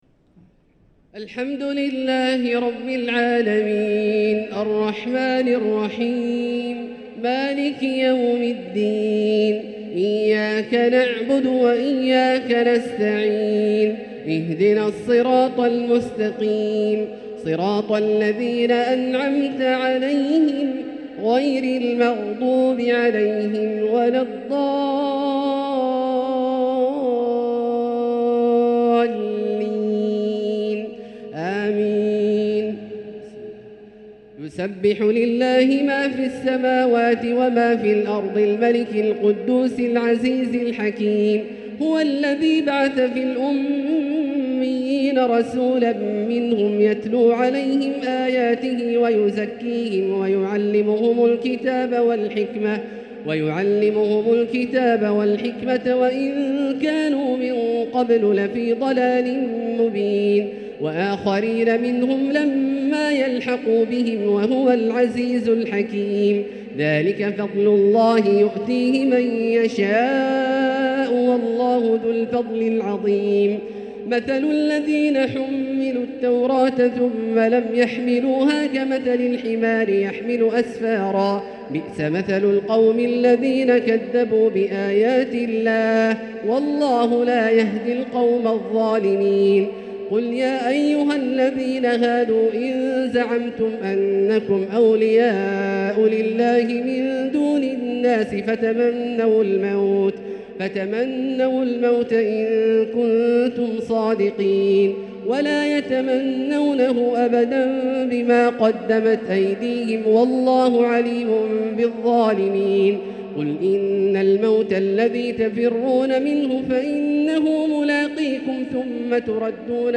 تلاوة بديعة لسورتي الجمعة و المنافقون | صلاة الجمعة 7-3-1445هـ > ١٤٤٥هـ > الفروض - تلاوات عبدالله الجهني